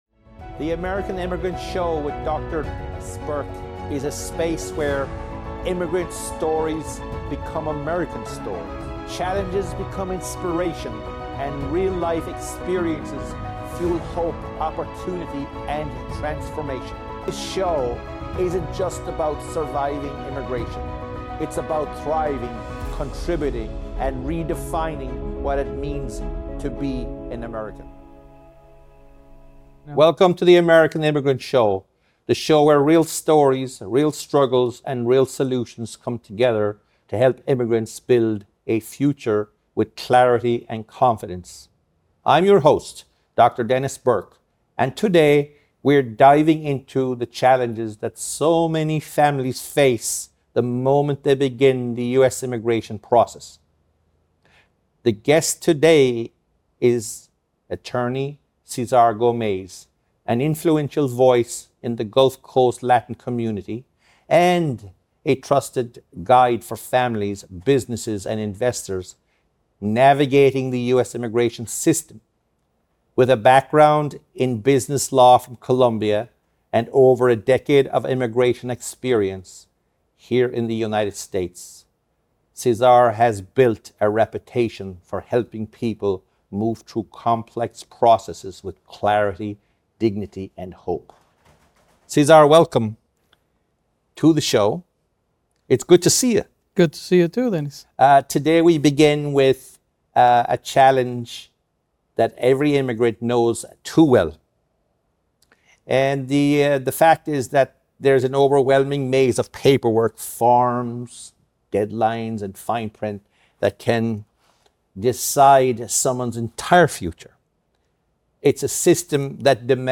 sits down with immigration attorney